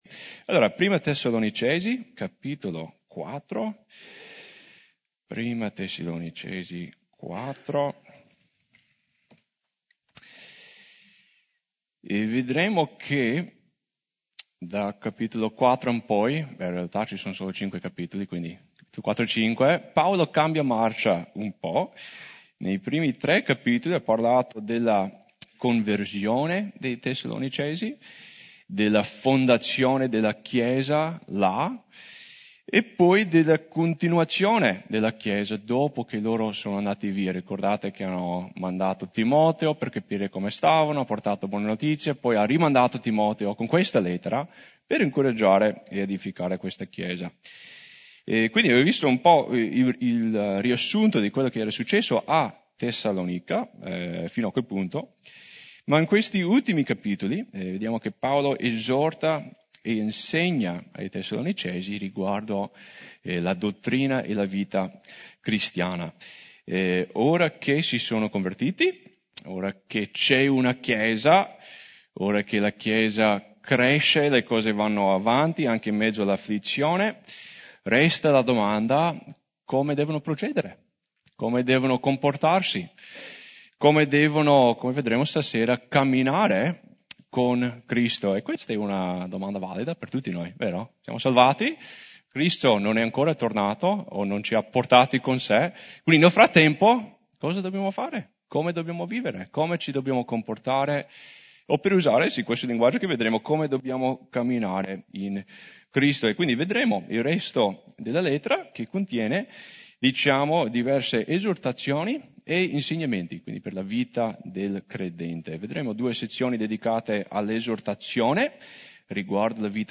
Guarda Ascolta Studio biblico di Mercoledì Da Categorie